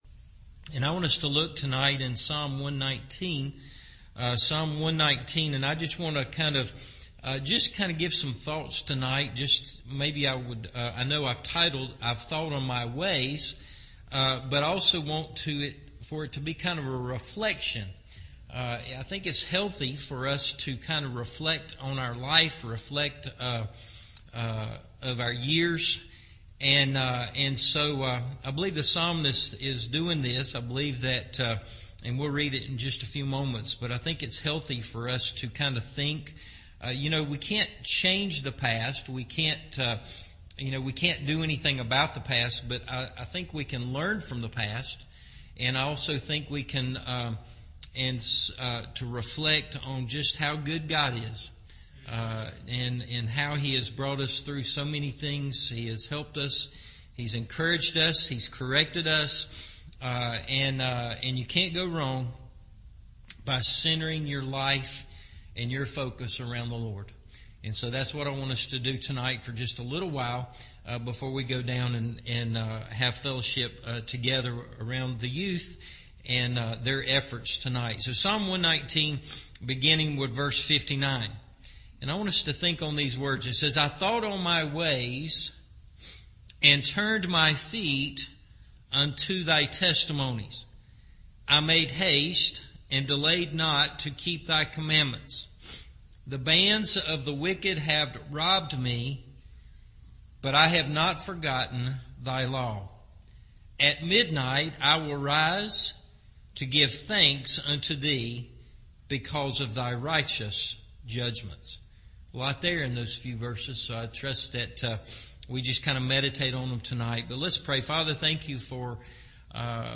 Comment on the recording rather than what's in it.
I Thought On My Ways – Evening Service